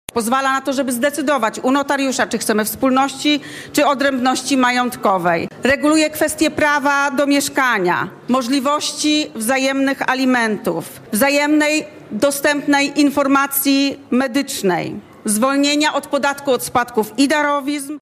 Wylicza pełnomocniczka rządu ds. równości Katarzyna Kotula.